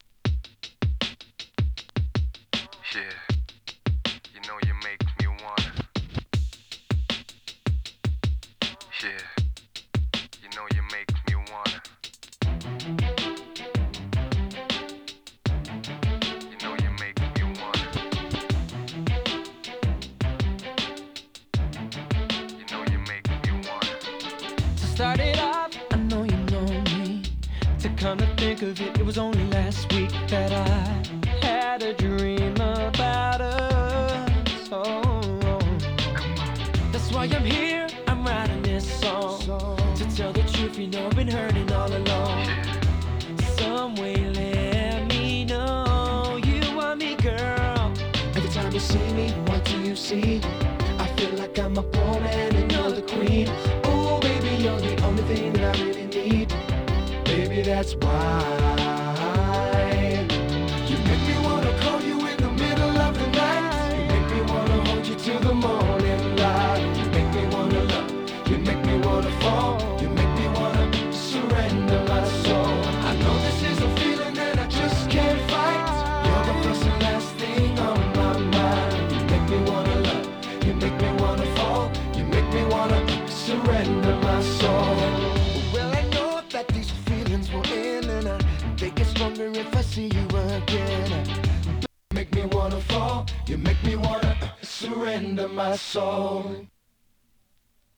切ないメロディーが胸に沁みる哀愁ミディアムR&B！ UKのイケメン・ボーカル・グループのヒット曲。